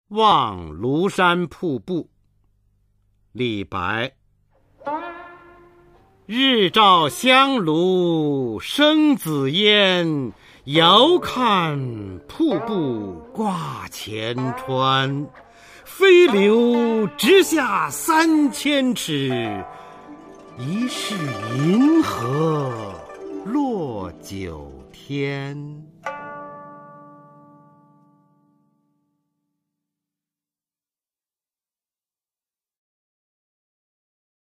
[隋唐诗词诵读]李白-望庐山瀑布 唐诗吟诵